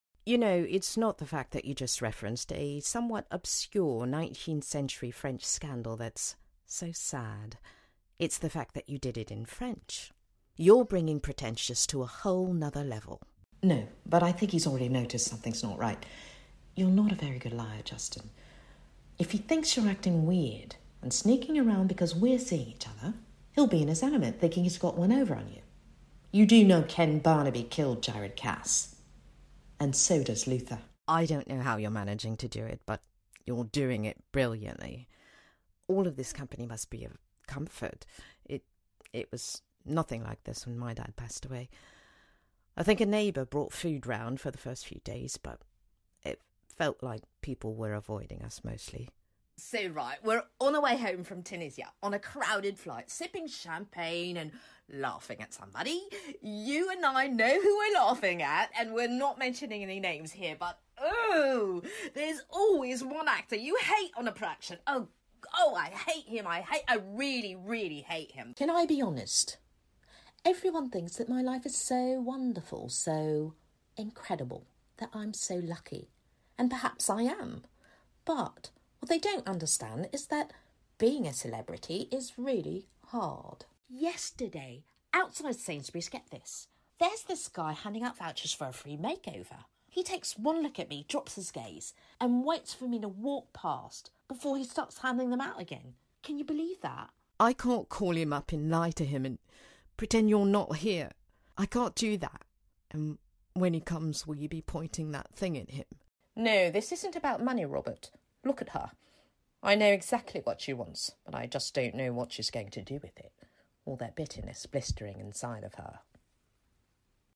SPOKEN VOICE REEL - UK ACCENT 082024